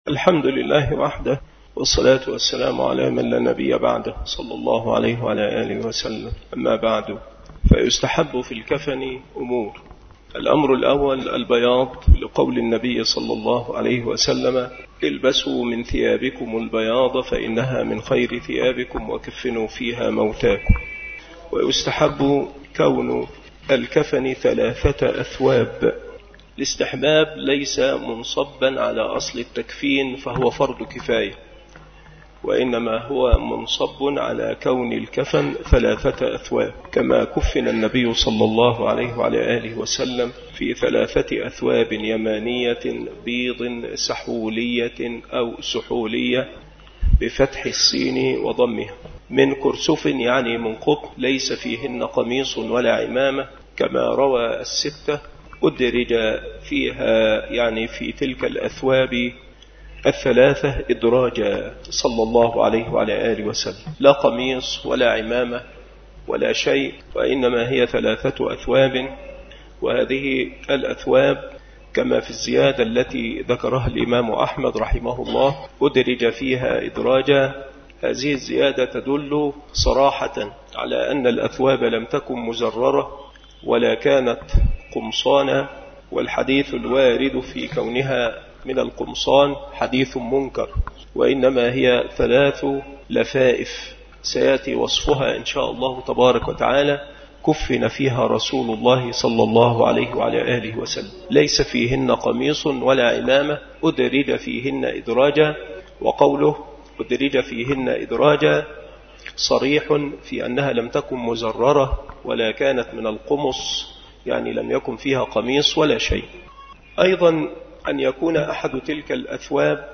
مكان إلقاء هذه المحاضرة بمسجد صلاح الدين بمدينة أشمون - محافظة المنوفية - مصر